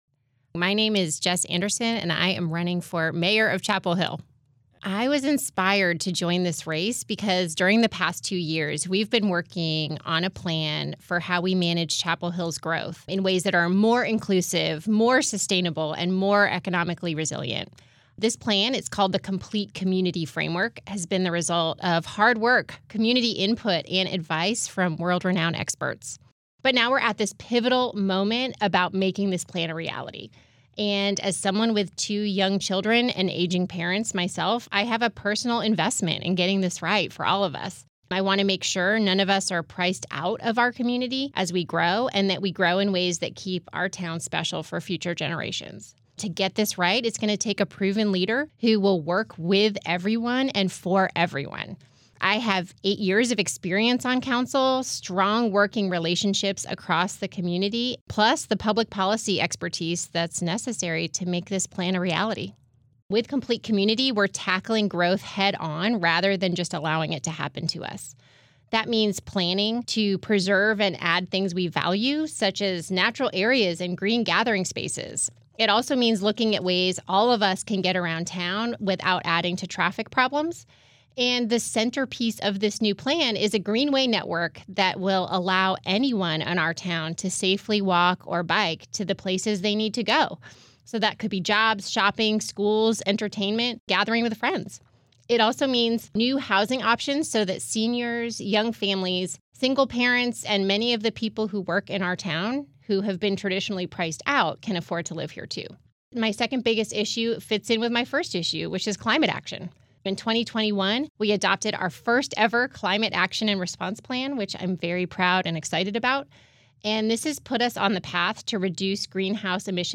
During this local election season, 97.9 The Hill and Chapelboro spoke with candidates for races representing Chapel Hill, Carrboro and Hillsborough. Each answered the same set of questions regarding their decision to run for elected office, their background in the community and what they wish for residents to think of when voting this fall. Their answers (lightly edited for clarity and brevity) are shared here, as well as links to their respective campaign websites or pages.